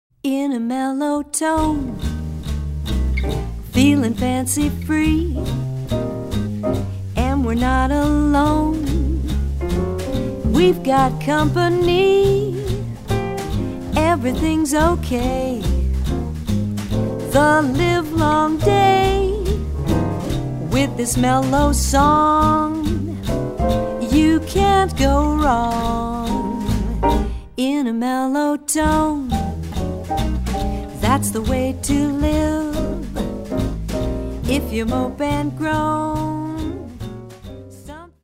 vocals, guitar
trumpet, flugelhorn
piano
bass
drums